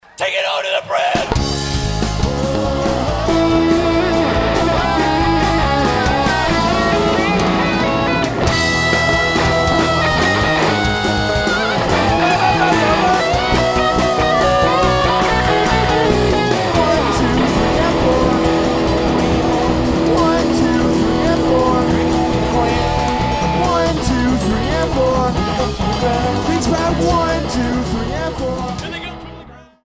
at Ultrasound Showbar in Toronto, Canada